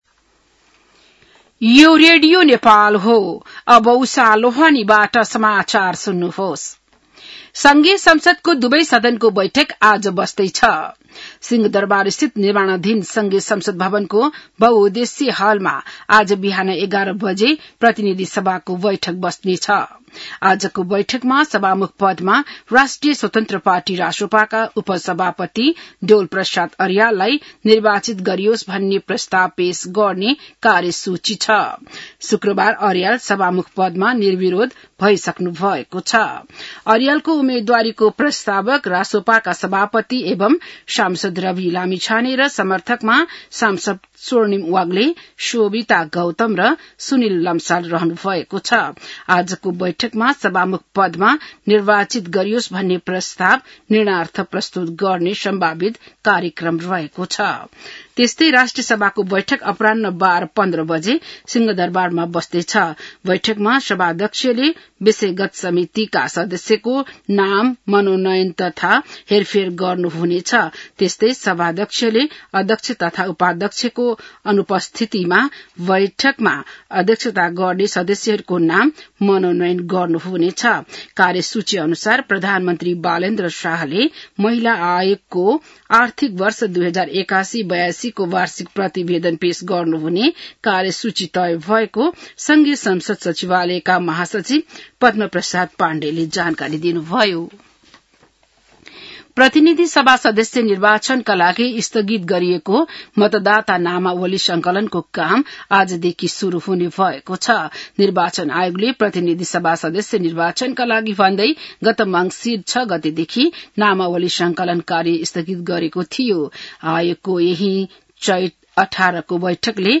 An online outlet of Nepal's national radio broadcaster
बिहान १० बजेको नेपाली समाचार : २२ चैत , २०८२